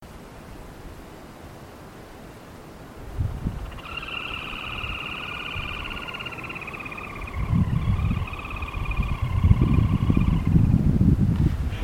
Sanã-vermelha (Rufirallus leucopyrrhus)
Respuesta al Playback
Nome em Inglês: Red-and-white Crake
Localidade ou área protegida: Parque Nacional Ciervo de los Pantanos
Condição: Selvagem
Certeza: Gravado Vocal